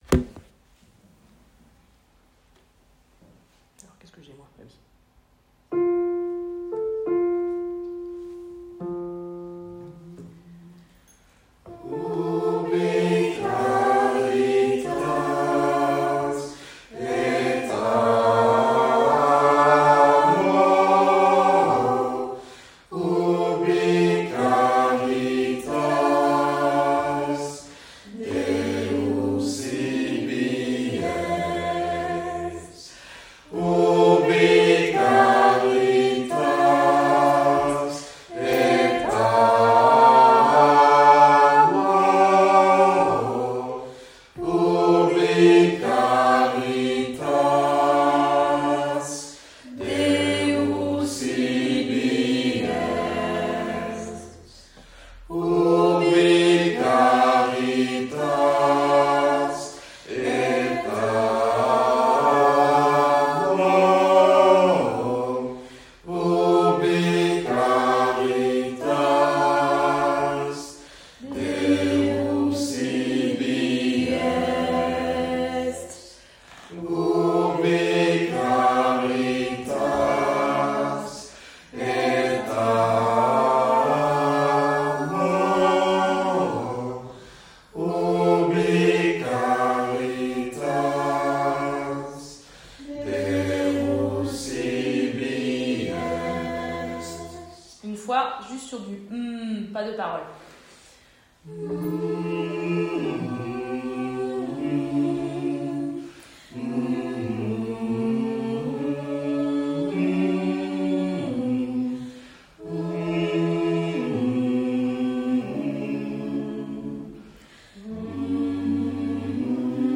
Chorale ouverte à toutes et à tous
Extrait audio de la chorale